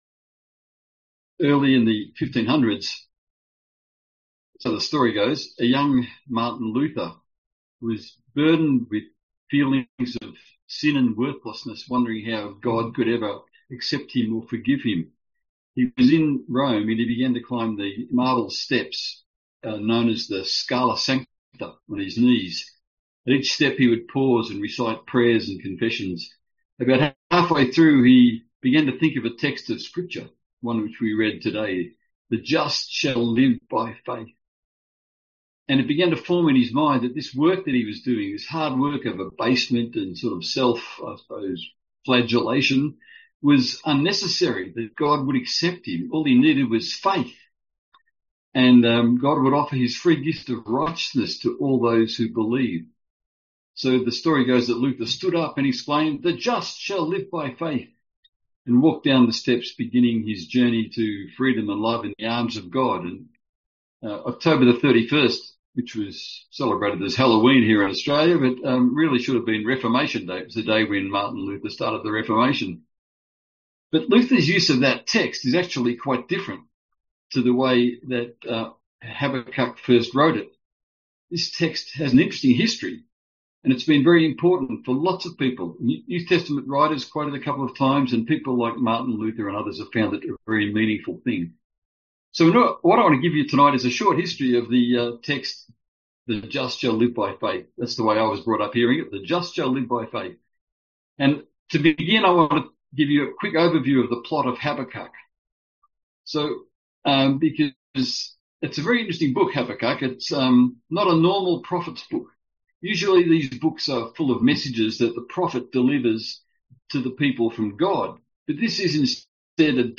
A sermon on Habakkuk 2:4